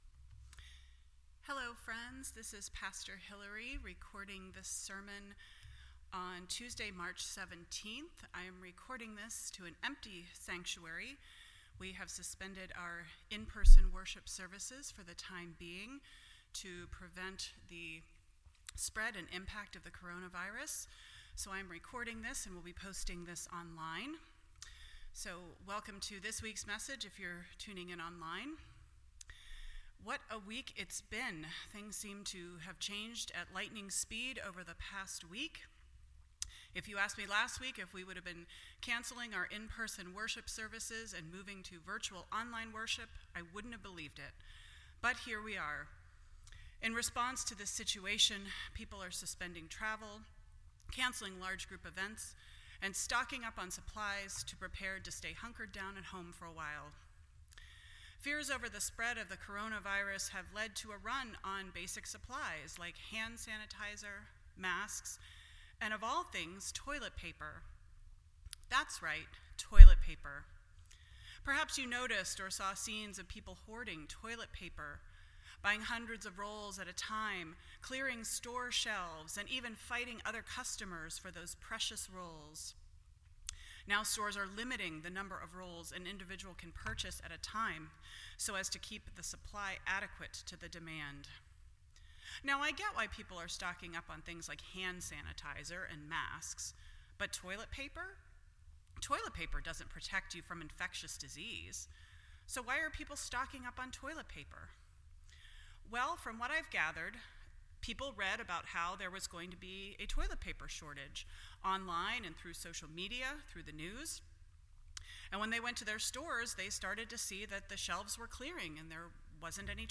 Lent 2020 Service Type: Sunday Morning %todo_render% Share This Story